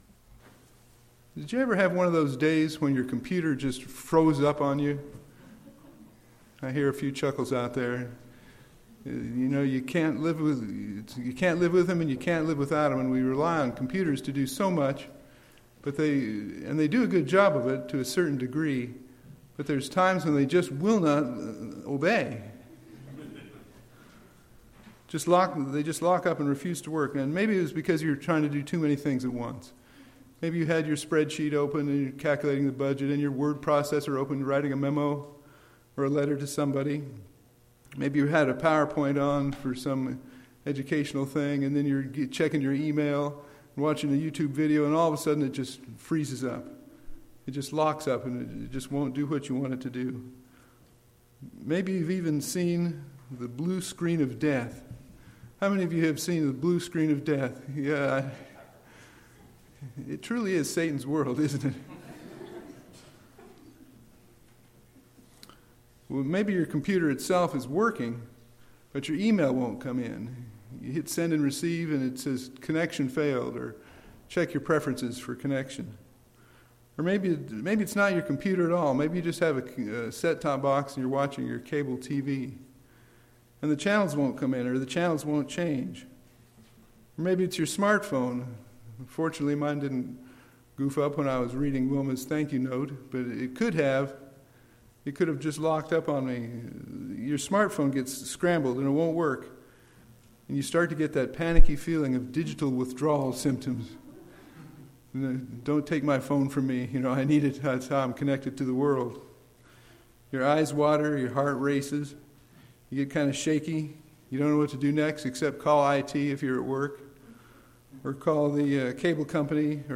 This sermon shows how the Spring Holy Days give us a much needed "reset" in our life.